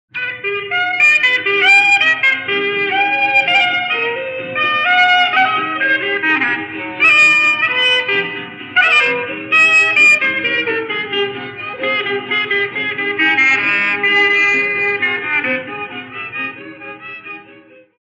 Clarinete
Instrumento de sopro cujo som é produzido por uma corrente de ar soprada através de uma paleta no corpo do instrumento. Possui formato tubular e é confeccionado em madeira onde se colocam as chaves. Muito utilizado por grupos de choro como instrumento solista.
clarinete.mp3